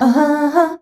AHAAA   C.wav